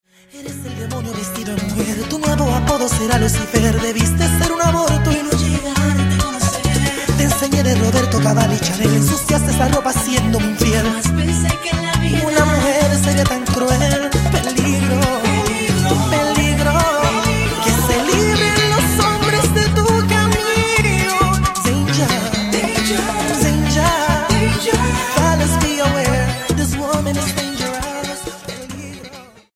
Bachata Charts - Februar 2011